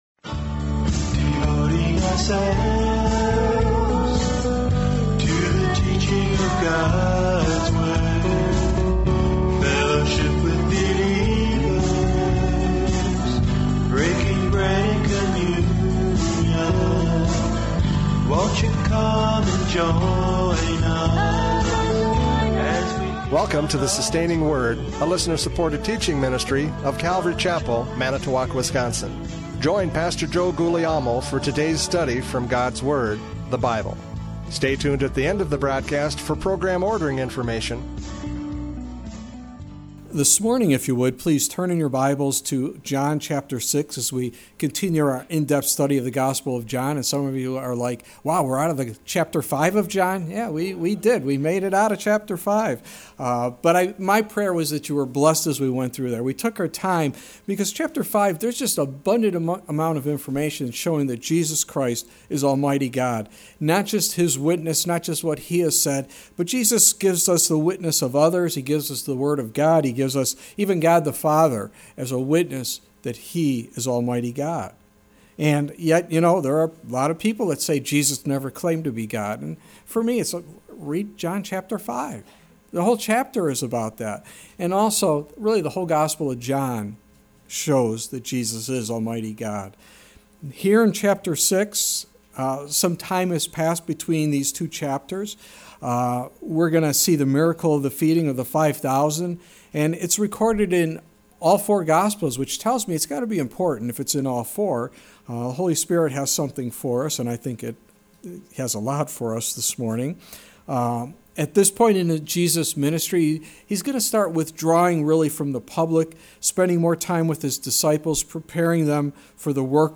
John 6:1-14 Service Type: Radio Programs « John 5:39-47 Testimony of Scripture!